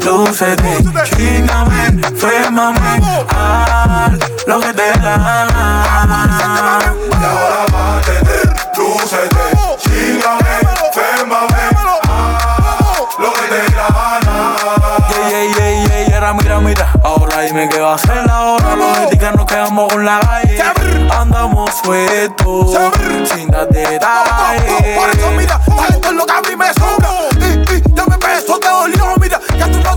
Страстные ритмы латино
Latin Urbano latino
Жанр: Латино